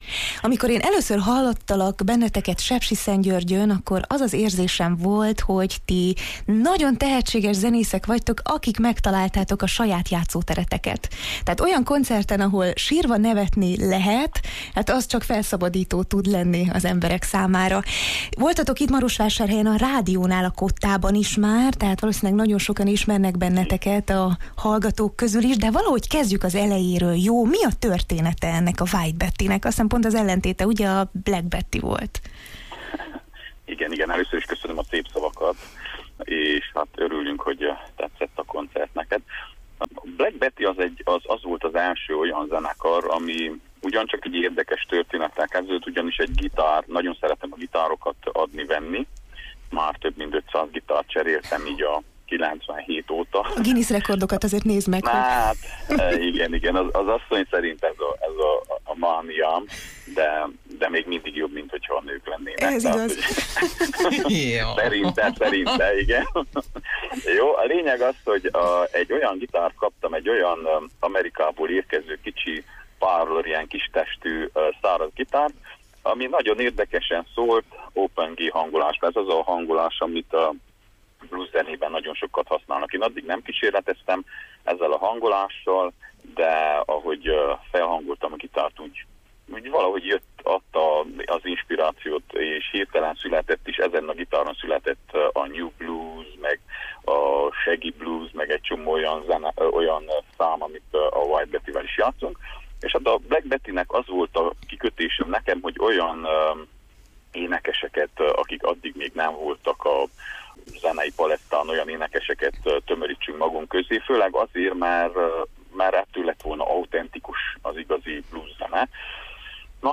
beszélgettünk az együttes megalakulásáról, nevük eredetéről, első koncertekről